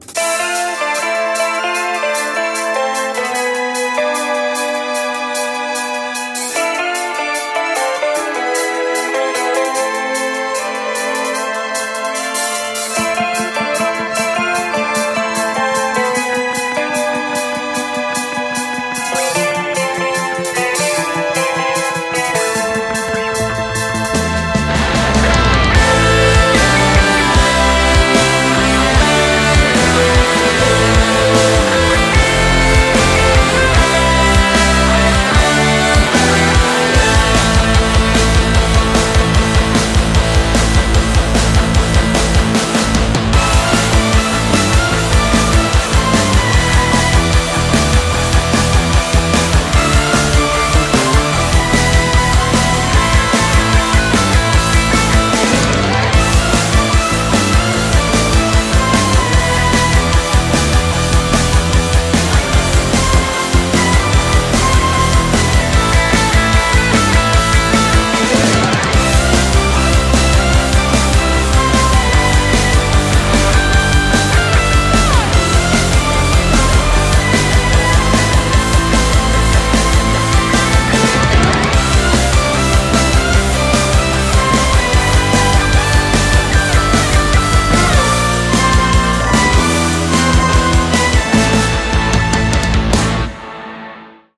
Существует и аранжировка в СимфоРок стиле